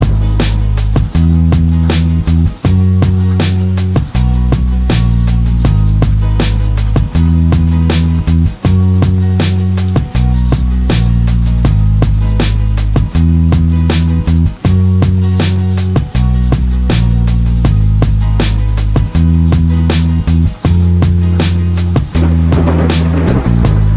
For Solo Rapper Pop